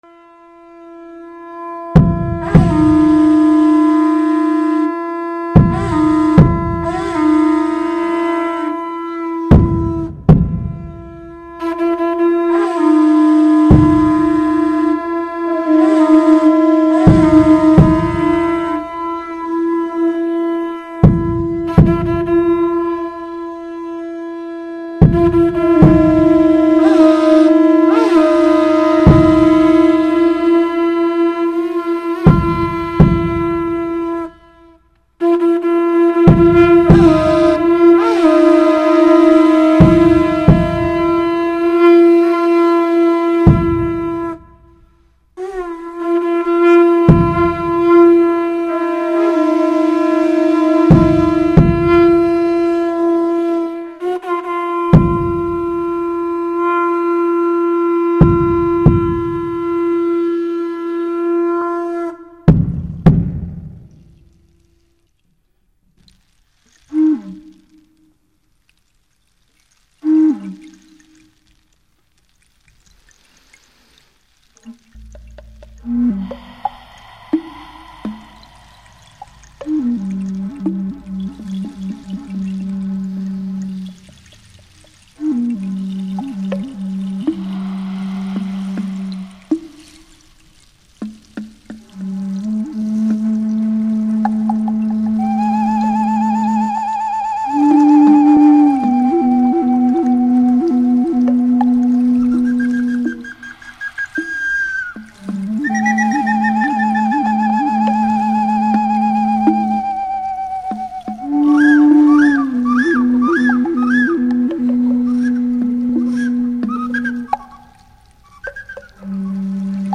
musica indigena